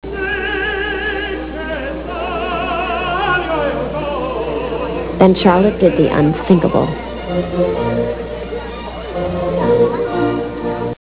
Classical/Instrumental
Comment: opera